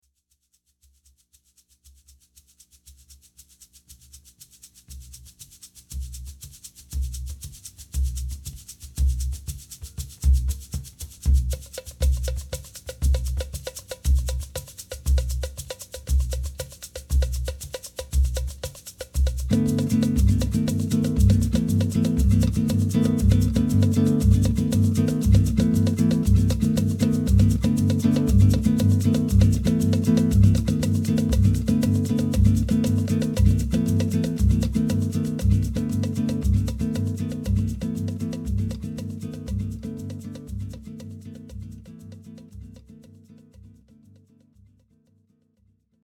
samba_intro.mp3